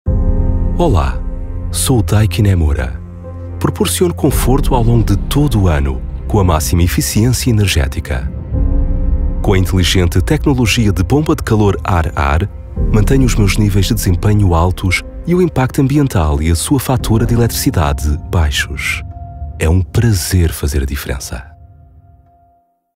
Vicoustic-Audiokabine, Neumann TLM103-Mikrofon, Neve 1073SPX-Vorverstärker, Apogee Duet 2-Schnittstelle, Highspeed-Kabelinternet.
BaritonBassTiefNiedrigSehr niedrig
FreundlichWarmRuhigFesselndVertrauenswürdig